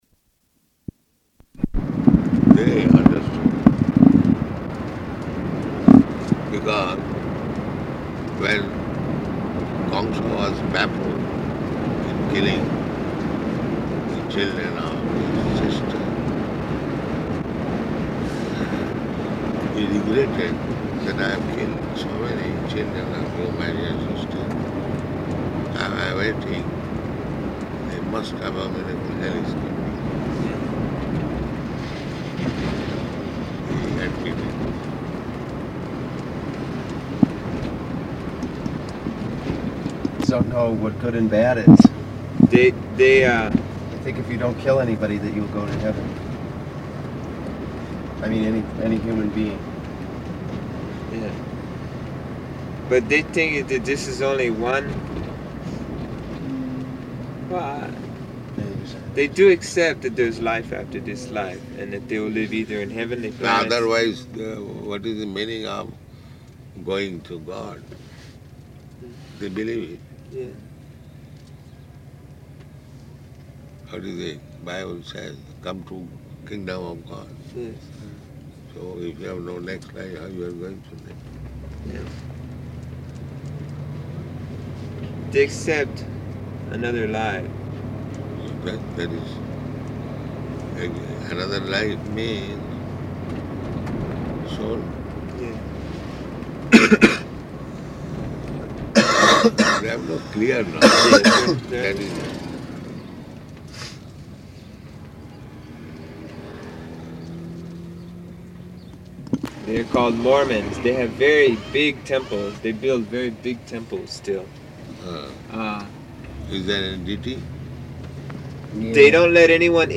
Morning Walk --:-- --:-- Type: Walk Dated: June 15th 1975 Location: Honolulu Audio file: 750615MW.HON.mp3 [in car] Prabhupāda: They understood.